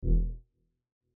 ブン(低音)
/ F｜演出・アニメ・心理 / F-80 ｜other 低音